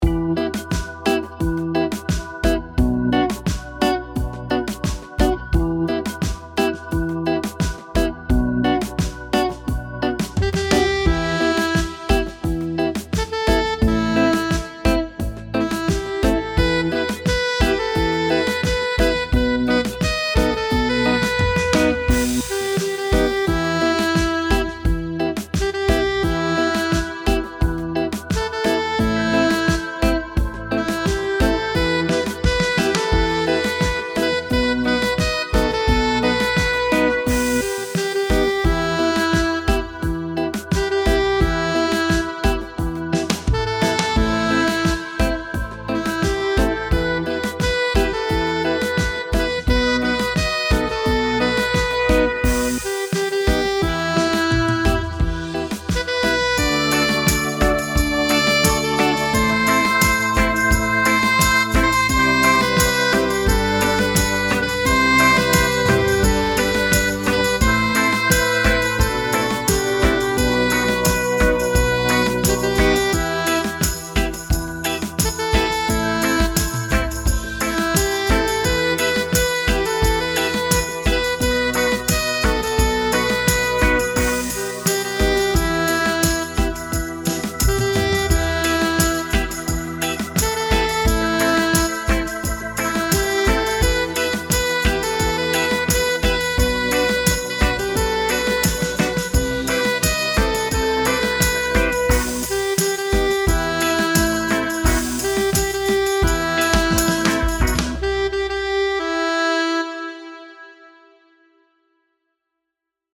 backing